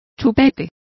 Complete with pronunciation of the translation of teat.